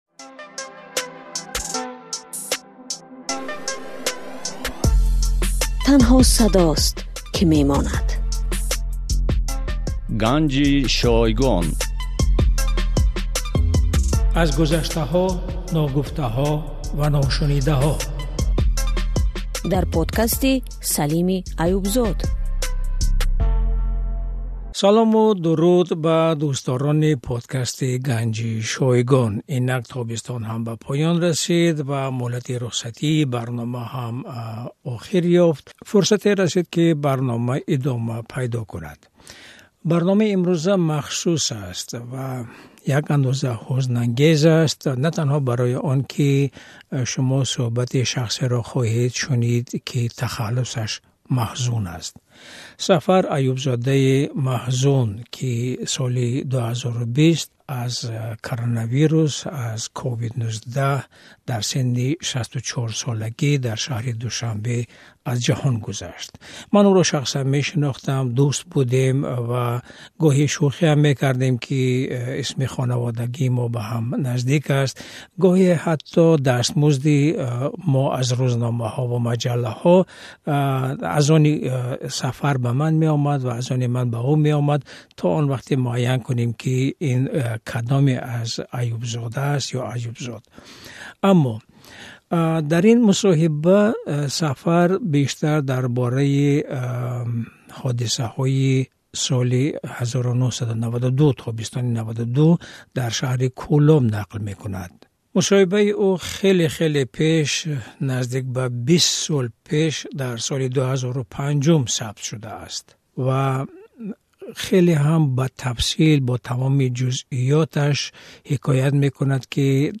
Гуфтугӯйи